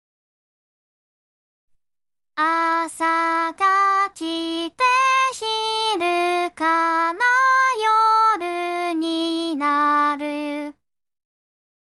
地声が好きではない人には期待できる(ALONEのマイクカバーにTASCAMのTM-82)
※ 実行結果はNEUTRINOで出力した音源をRVCで変換している